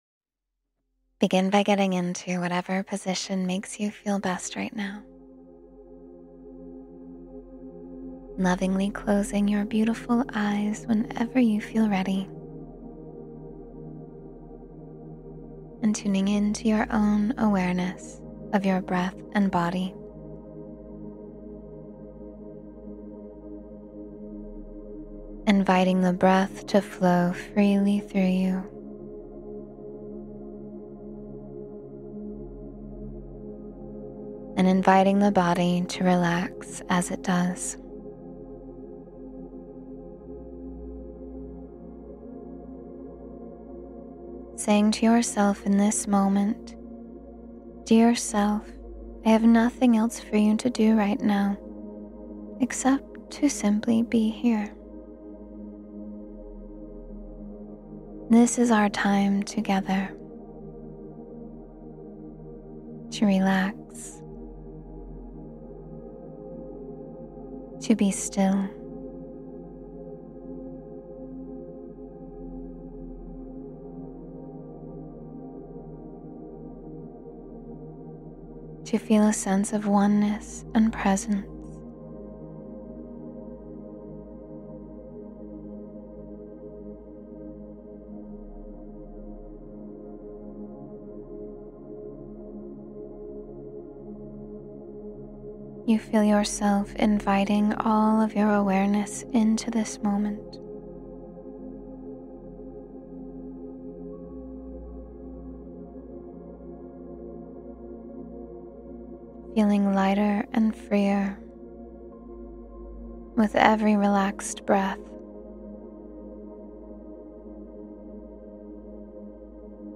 Step Boldly into the Next Chapter of Life — Meditation for Courage and New Beginnings